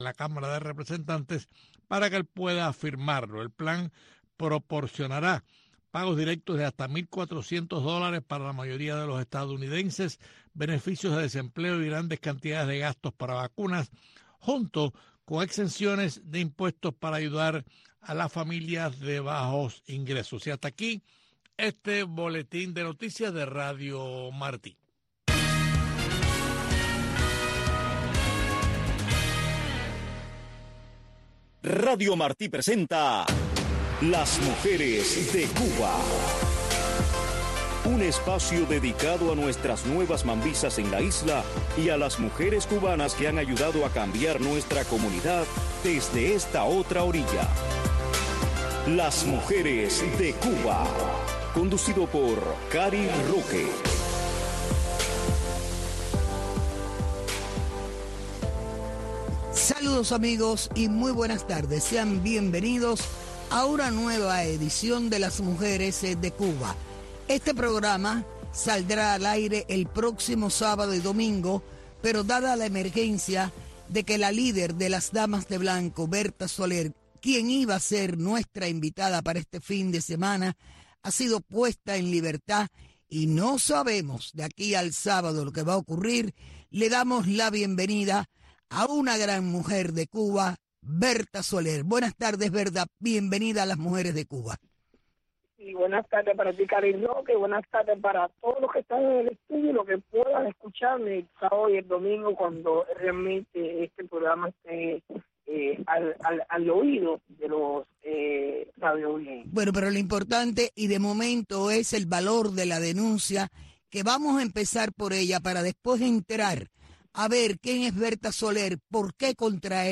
Un programa narrado en primera persona por las protagonistas de nuestra historia.